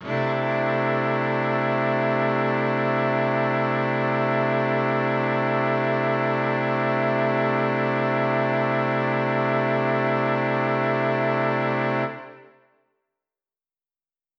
SO_KTron-Cello-C6:9.wav